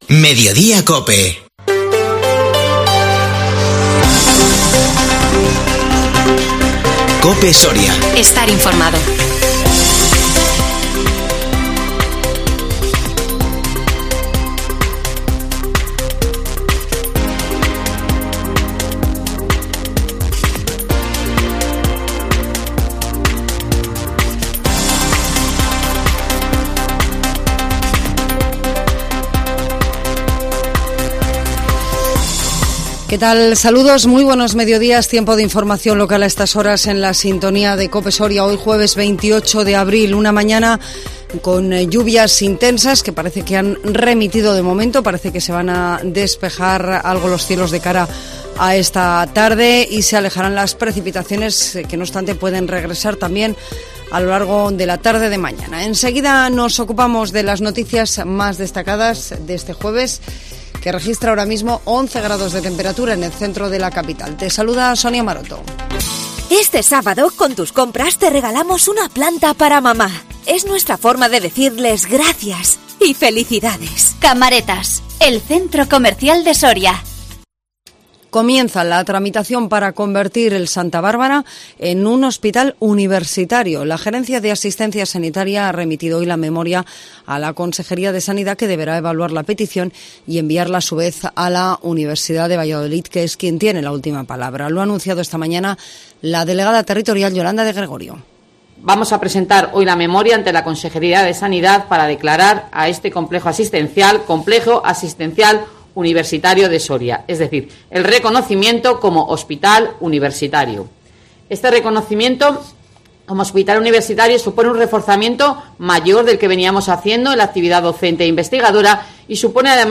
INFORMATIVO MEDIODÍA COPE SORIA 28 ABRIL 2022